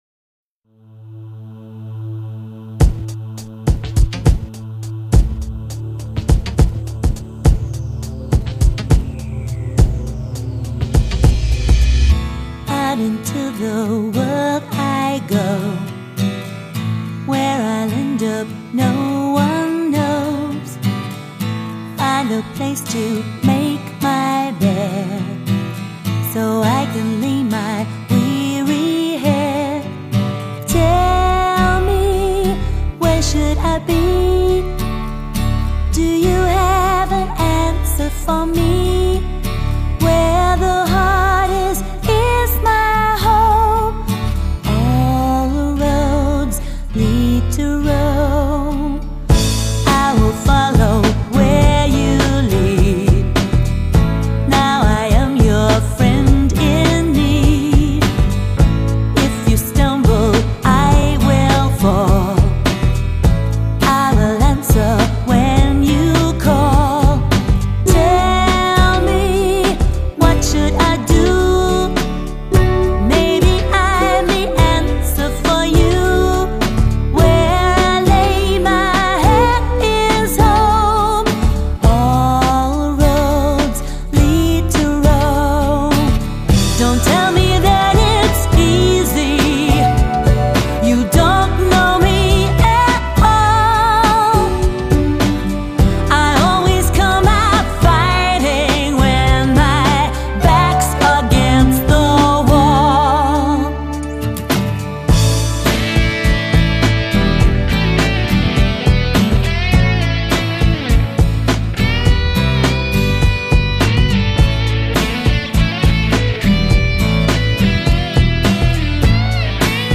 voce e chitarra
piano, percussioni, tastiere
basso elettrico
contrabbasso
batteria
sax tenore, sax soprano, flauto
tromba
trombone